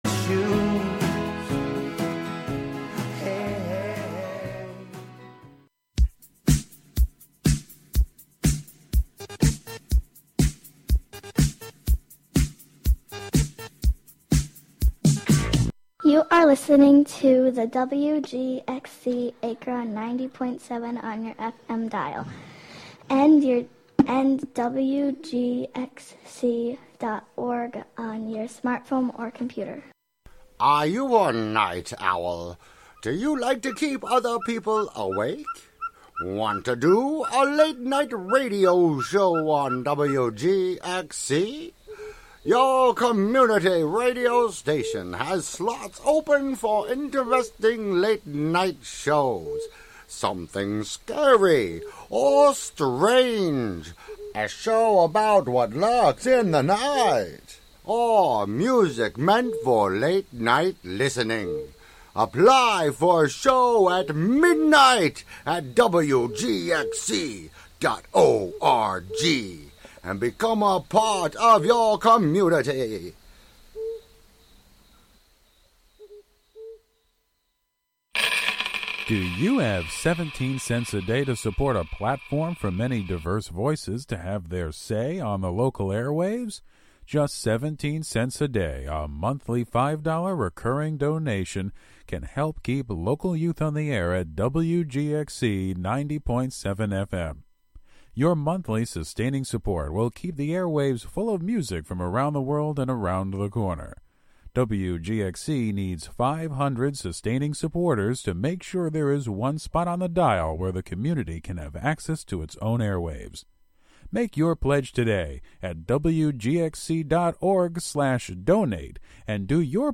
Show includes local WGXC news at beginning and midway through.